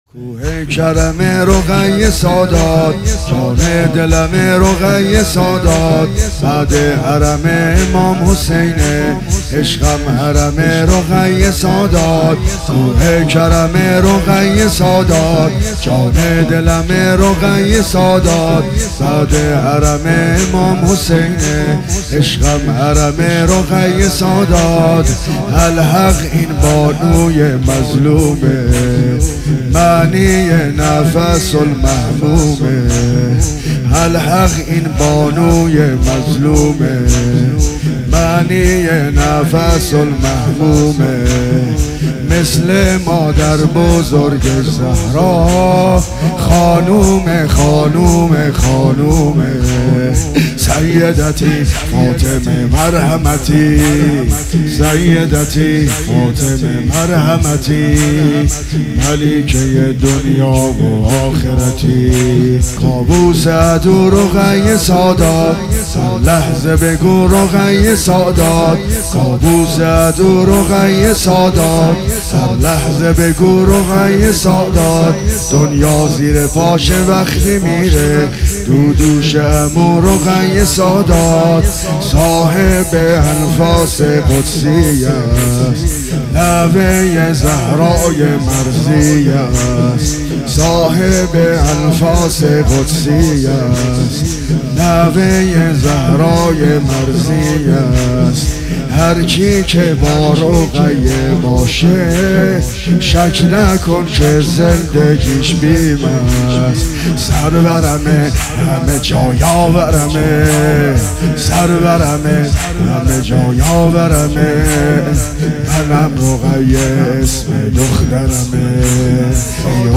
دانلود صوتی و ویدئویی کوه کرمه رقیه خاتون عبدالرضا هلالی به همراه متن کوه کرمه رقیه خاتون محرم 1400 مداحی زمینه | پلان 3